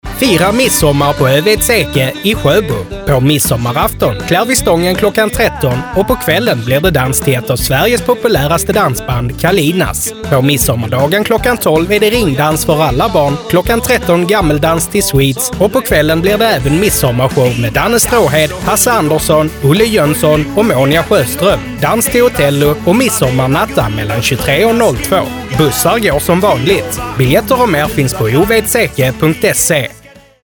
Här kommer årets radioreklam!